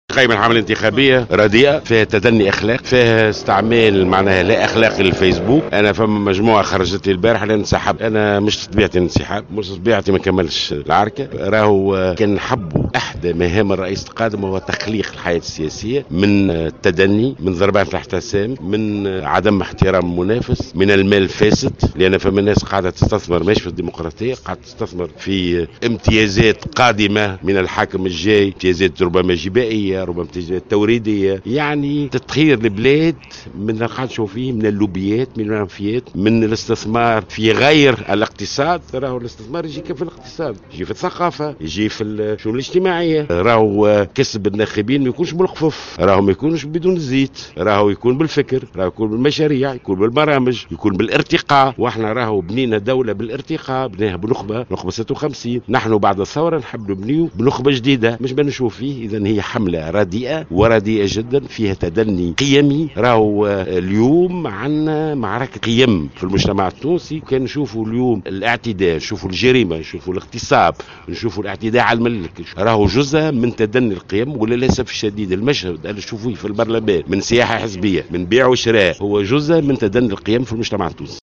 ونفى جلول، في تصريح للجوهرة أف أم، على هامش اختتام حملته الانتخابية، في المنستير، اليوم الجمعة، صحة الأخبار المتداولة عبر الفيسبوك بخصوص انسحابه من السباق الرئاسي، معتبرا أن بعض المترشحين يستخدمون منصة التواصل الاجتماعي المذكورة بطريقة "لا أخلاقية".